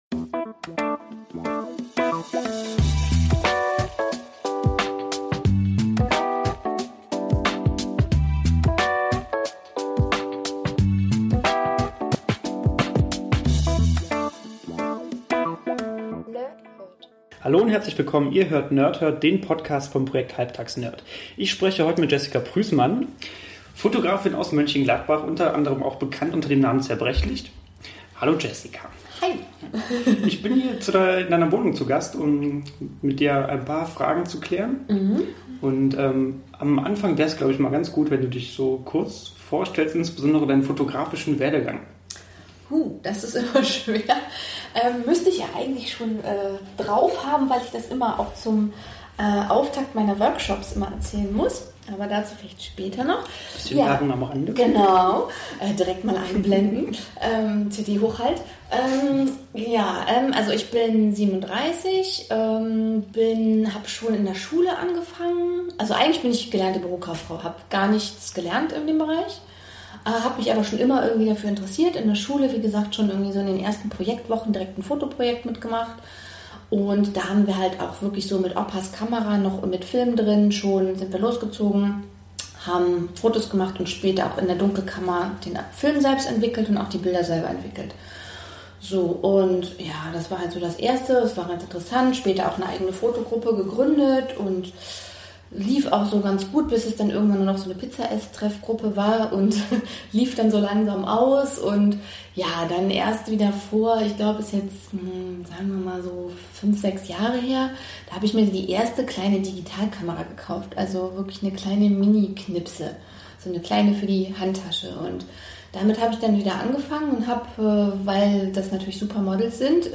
Ich spreche bei einem Glas Wein mit ihr über ihre Anfänge, die Bedeutung der Fotografie und die ewige Diskussion ob Analog oder Digital.